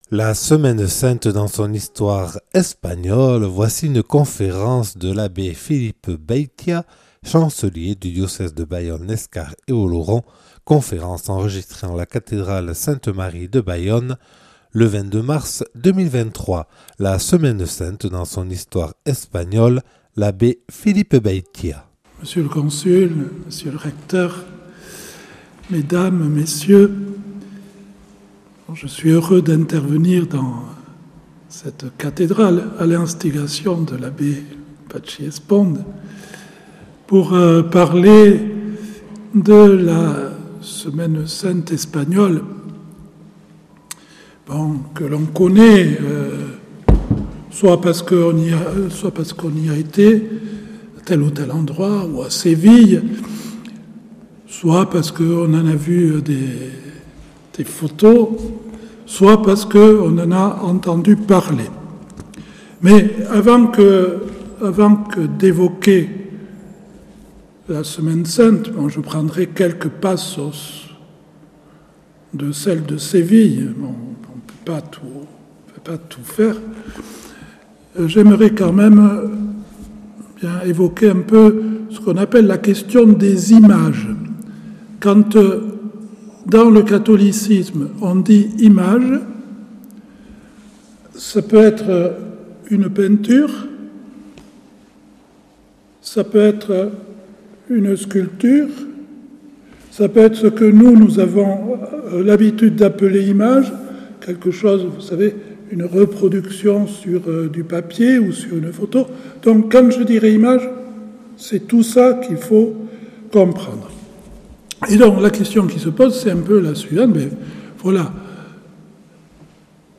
Une conférence
enregistrée le 22 mars 2023 à la cathédrale Sainte-Marie de Bayonne.